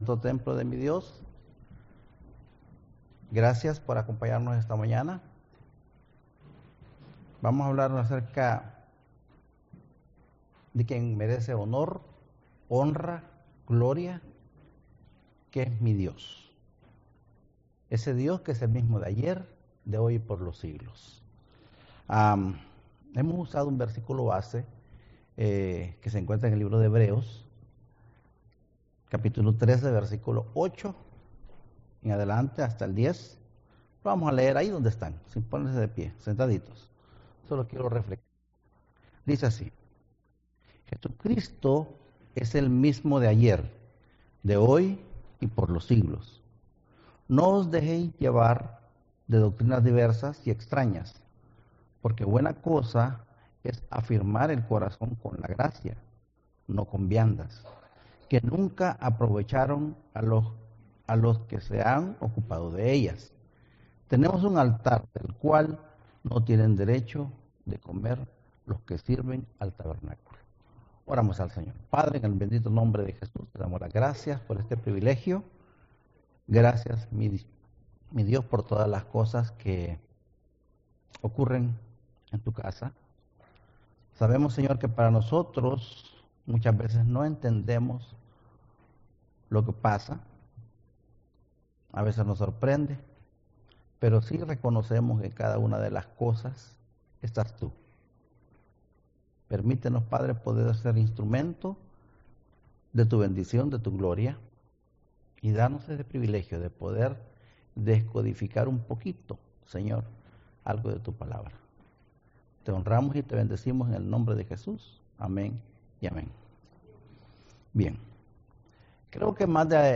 2018 Current Sermon Mi Dios
Guest Speaker